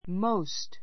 móust